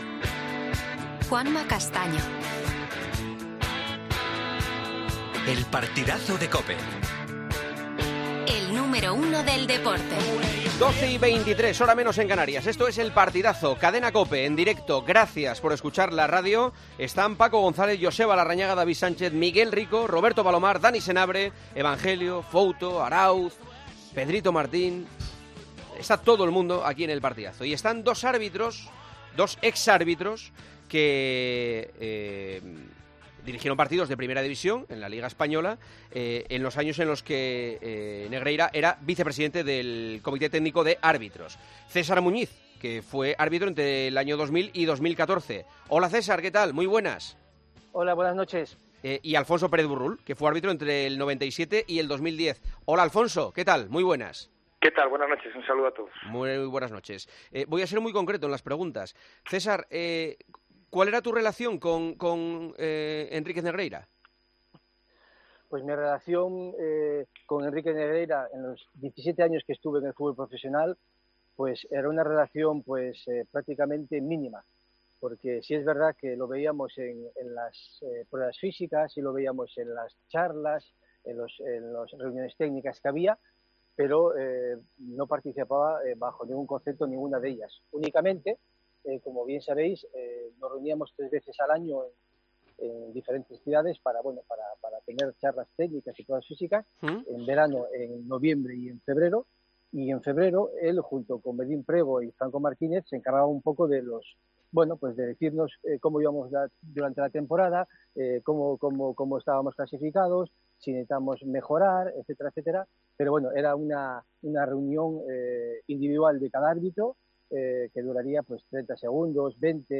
En El Partidazo de COPE hablamos con los excolegiados, Alfonso Pérez Burrull, César Muñiz Fernández y Antonio Jesús López Nieto.